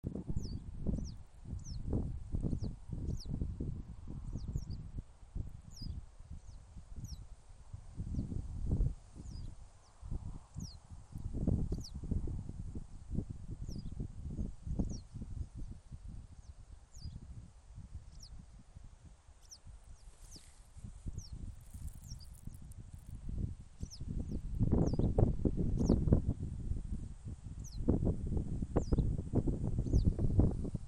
Yellow Wagtail, Motacilla flava
NotesPēc silueta ļoti lidzīgs baltajai cielavai- ķermenis slaids, krūtiņa dzeltena, aste pagara, ejot pāri pļavai man virs galvas lidinājās un pavadīja čivinot, ik pa laikam piesēžot zālē vai uz ruļļiem,ierakstā gan liels vējš, bet tie vairāk būtu uztraukuma saucieni.Redzēti divi putni